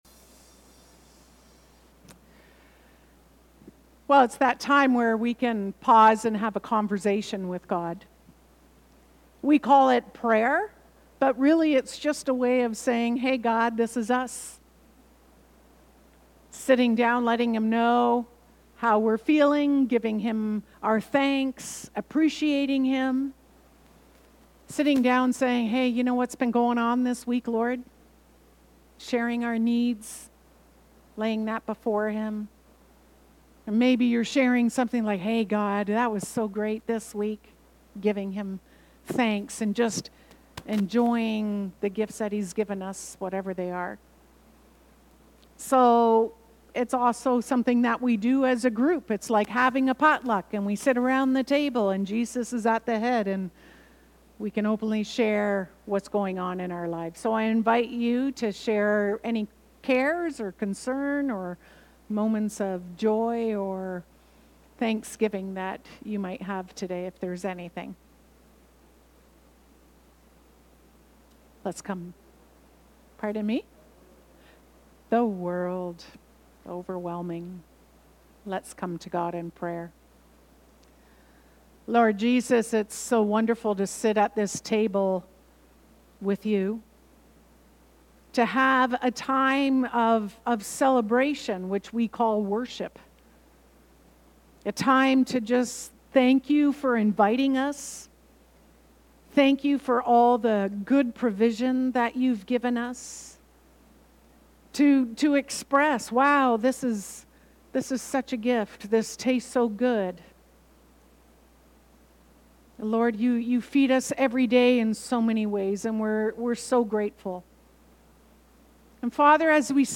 Listen to Message
Knox Binbrook worship service June 22, 2025